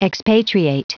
Prononciation du mot expatriate en anglais (fichier audio)
Prononciation du mot : expatriate